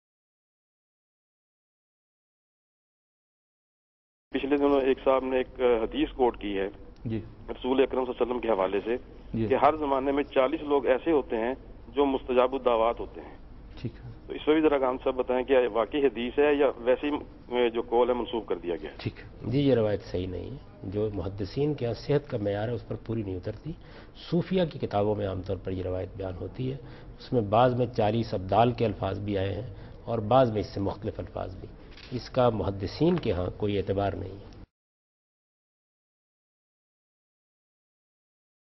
Javed Ahmad Ghamidi answers a question "Who Fulfills our Prayers?" in program Deen o Daanish on dunya news.
جاوید احمد غامدی دنیا نیوز کے پروگرام دین و دانش میں ایک سوال کہ "ہماری دعائیں کون قبول کرتا ہے؟" کا جواب دیتے ہیں۔